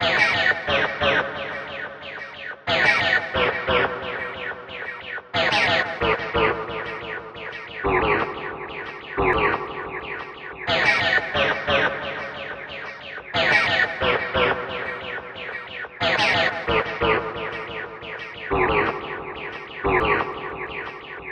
Tag: 90 bpm Electro Loops Synth Loops 1.79 MB wav Key : A